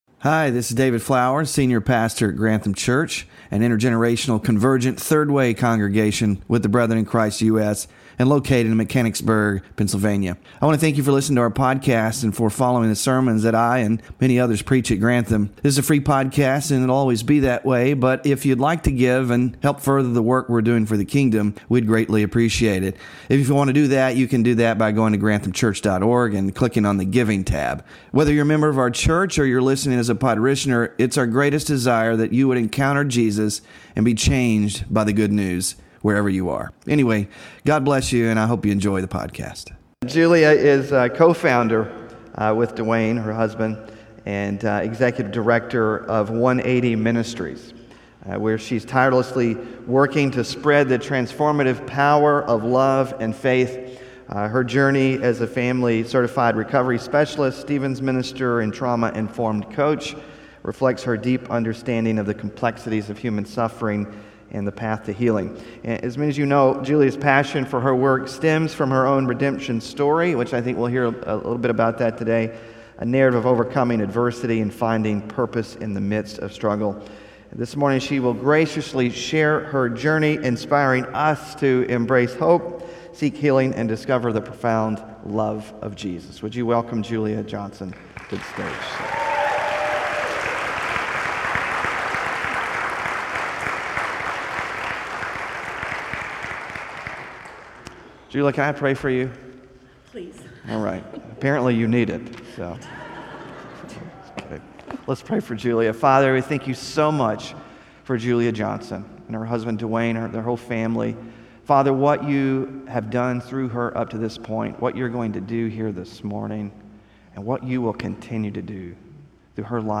WORSHIP RESOURCES MOVED BY COMPASSION: LIVING & LOVING LIKE JESUS – SERMON SLIDES (6-29-25) BULLETIN (6-29-25)